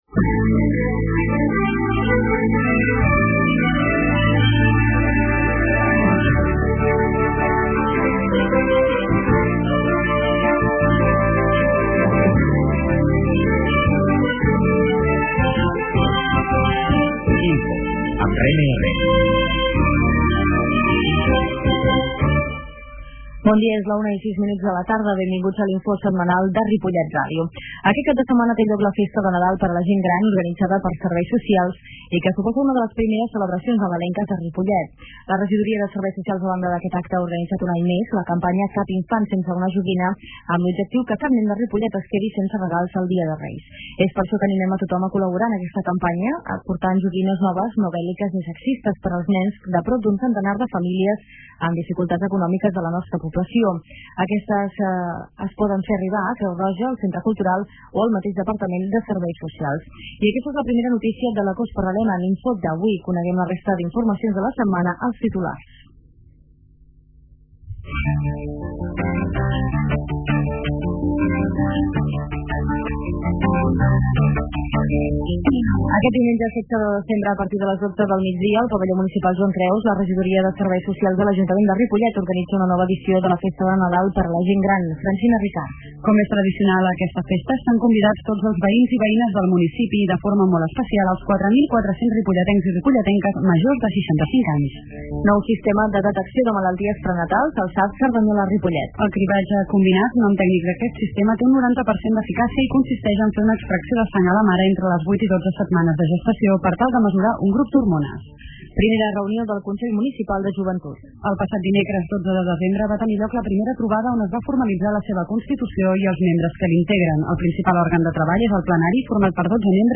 Aquesta setmana amb l'entrevista a la regidora de Planificaci�, Gesti� Urban�stica, Manteniment, Parcs i Jardins, Rosa Mart�n. Redifusi� i desc�rrega per Internet En redifusi� els divendres a les 18 hores i diumenges a les 13 hores.
La qualitat de so ha estat redu�da per tal d'agilitzar la seva desc�rrega.